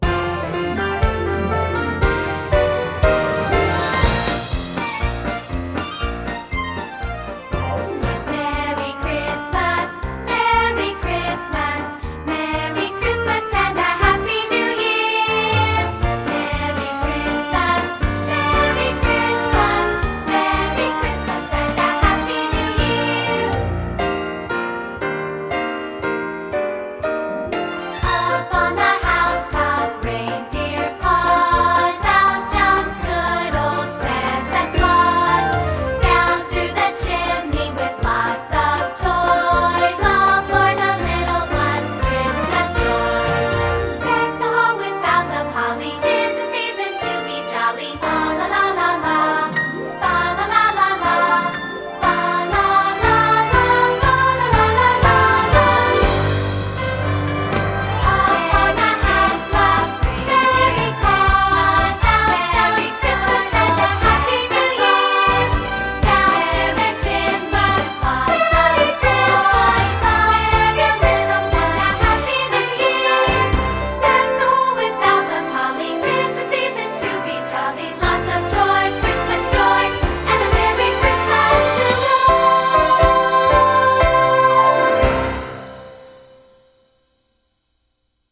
secular choral